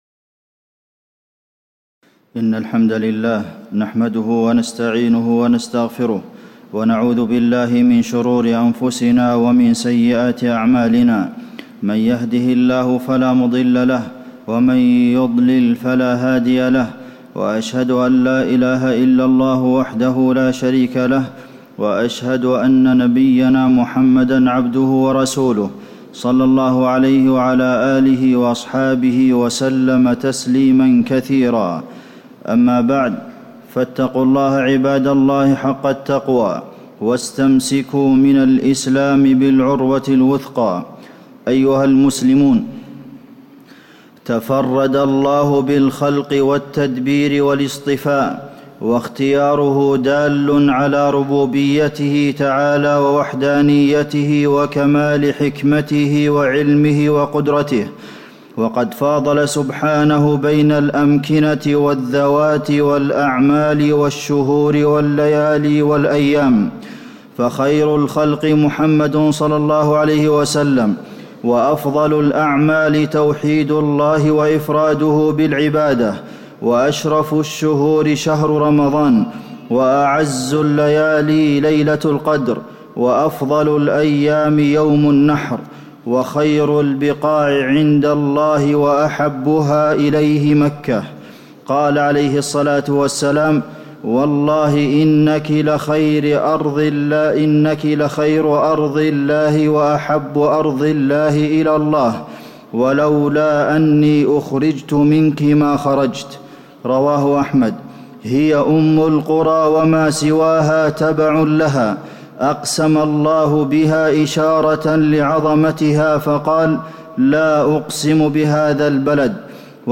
تاريخ النشر ٢٣ ذو القعدة ١٤٣٧ هـ المكان: المسجد النبوي الشيخ: فضيلة الشيخ د. عبدالمحسن بن محمد القاسم فضيلة الشيخ د. عبدالمحسن بن محمد القاسم فضل مكة والمسجد الحرام